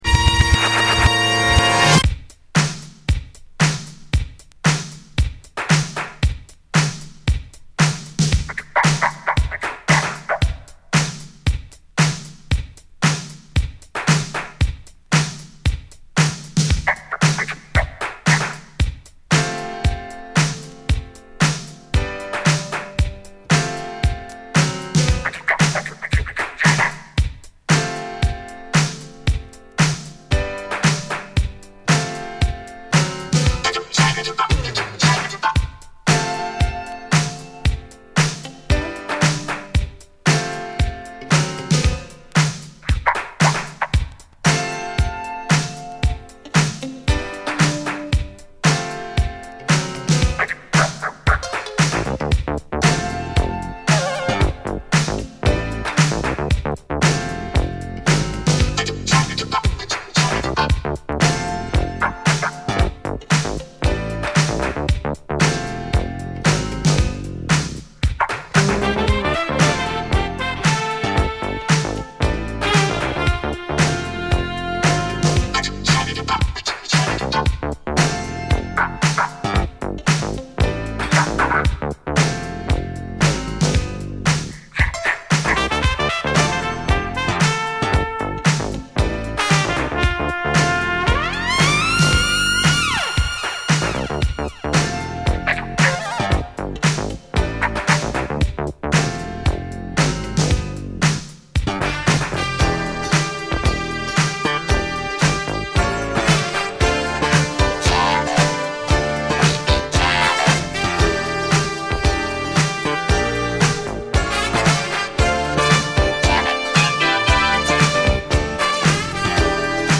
この時代らしいアーバンなエレクトロ・フュージョンを披露。